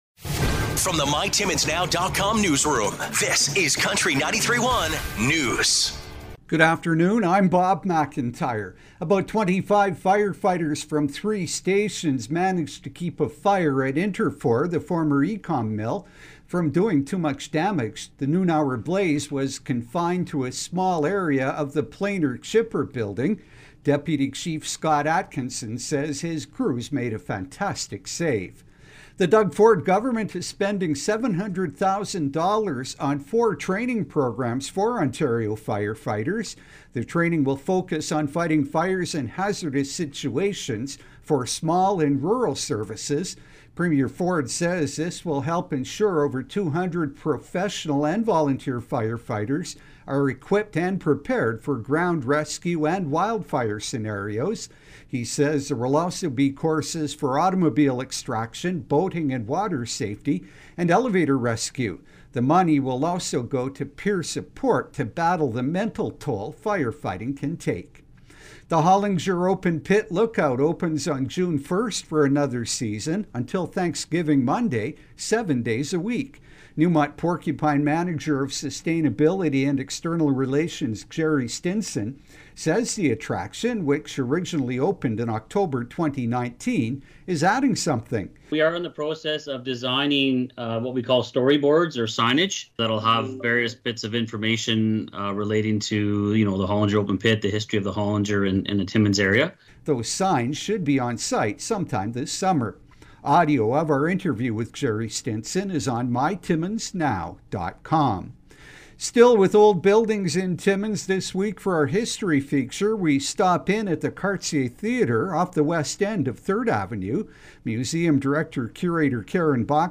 5:00pm Country 93.1 News – Mon., May 15, 2023